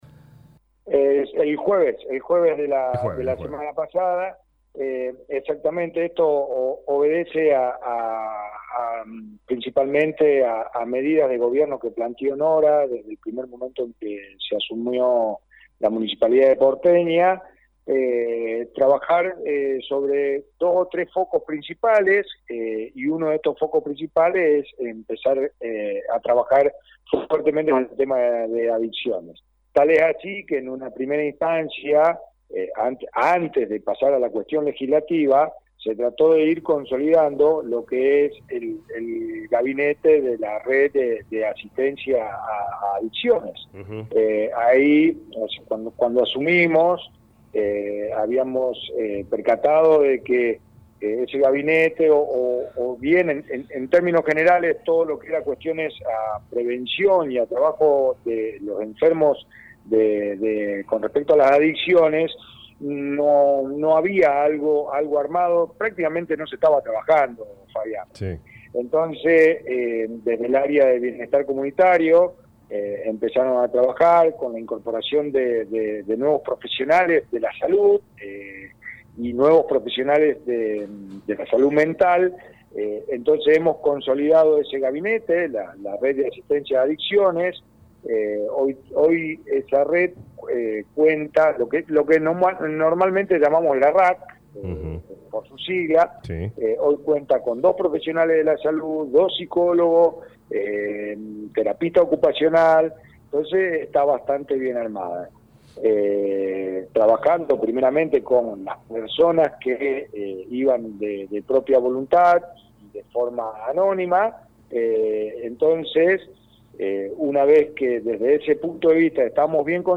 El Presidente del HCD Fabio Monzoni explicó los aalcaances de la ordenanza en dialogo con LA RADIO 102.9.